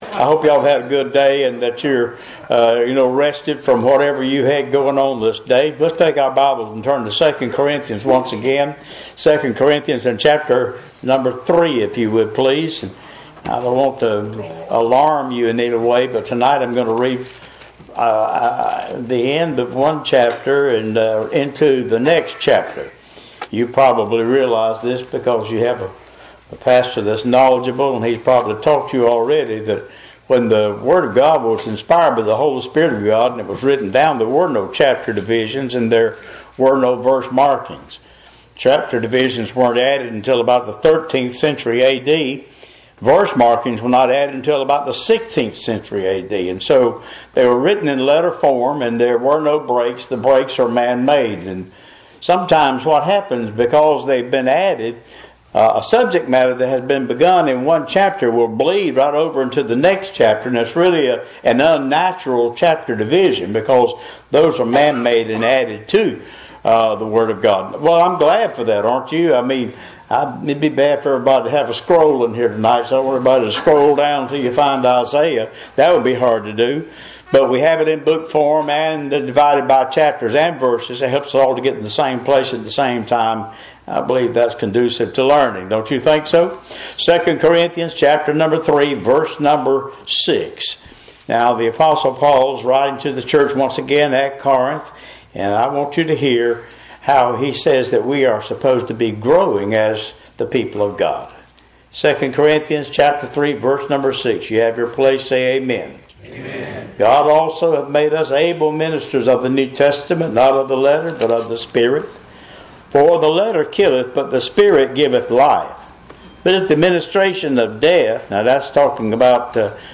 Revival – Tuesday Evening